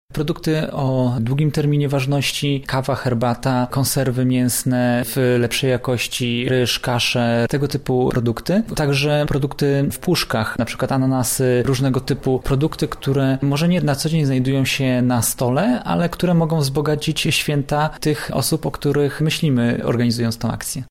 O zasługach Tadeusza Kościuszki w walce o zachowanie niepodległości Polski oraz ich wpływie na kształtowanie świadomości narodowej dyskutowano podczas sesji zorganizowanej w należącym do Uniwersytetu Marii Curie-Skłodowskiej Dworku Kościuszków.
Okazją do spotkania była dwusetna rocznica śmierci Generała.